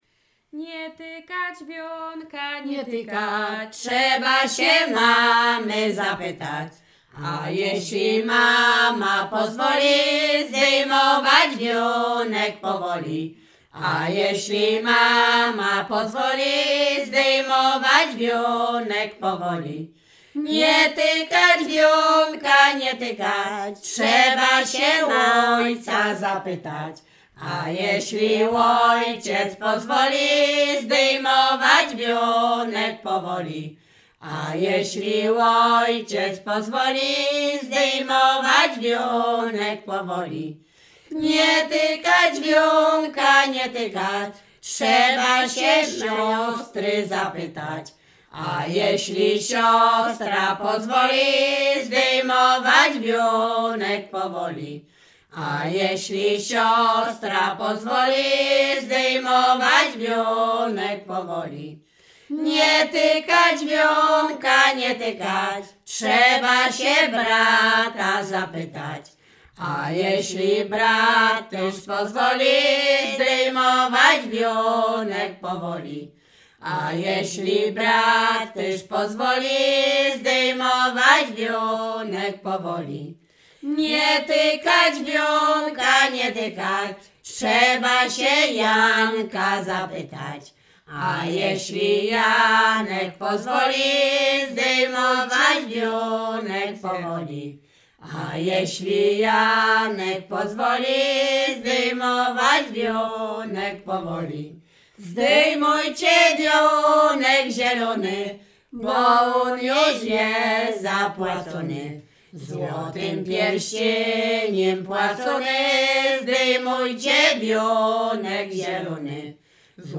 Śpiewaczki z Mroczek Małych
województwo łodzkie, powiat sieradzki, gmina Błaszki, wieś Mroczki Małe
Weselna
weselne przyśpiewki